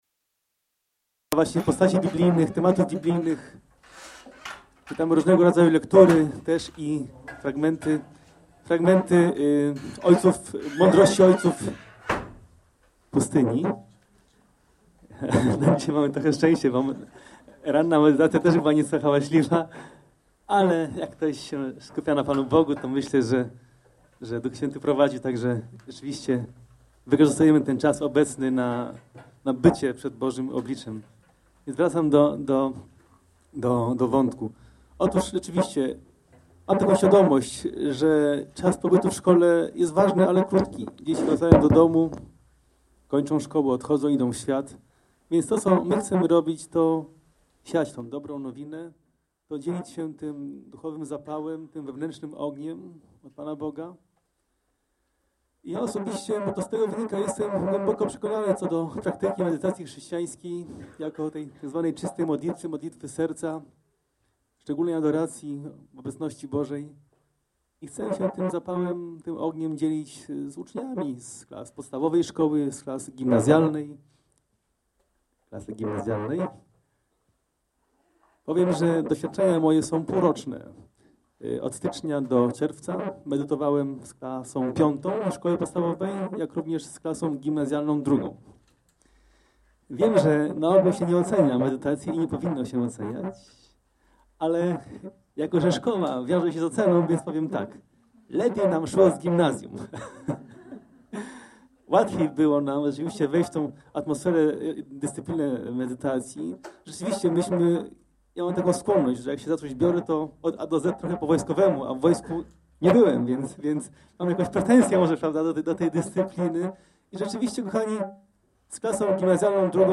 Pliki mp3 z nagrań konferencji: